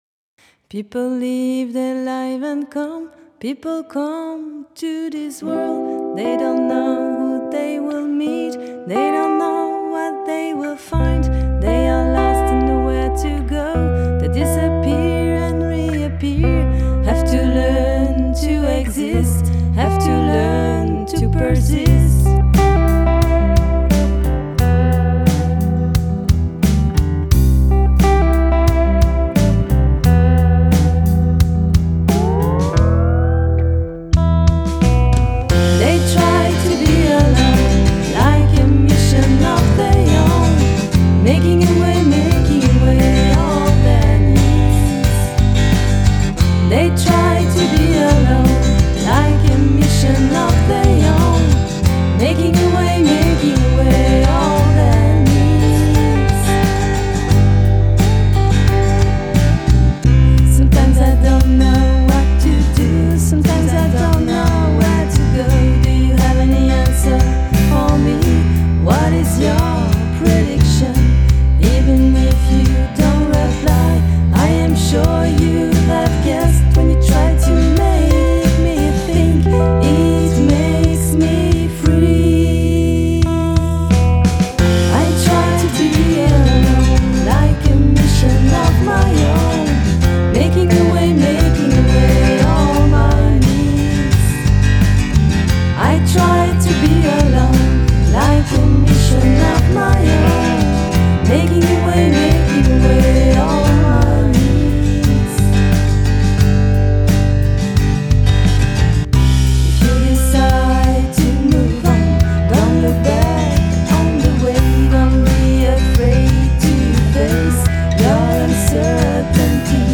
Chant
Guitares
Basse
Batterie